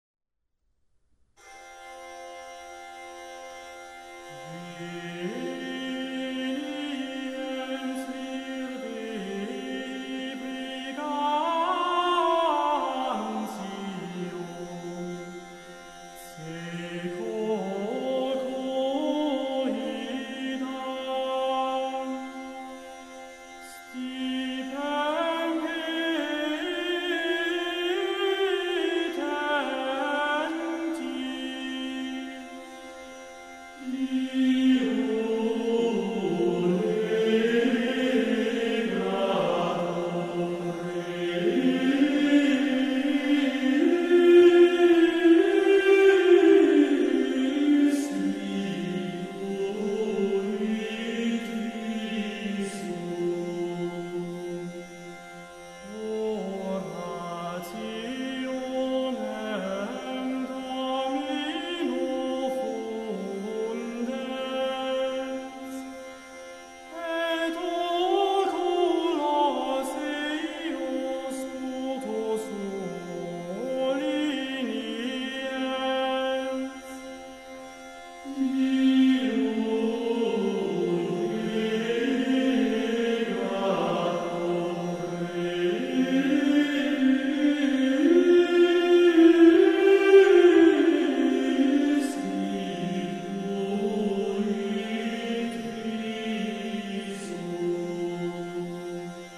Responsorium
(Uitvoering: Ordo Virtutum, Edition Raumklang)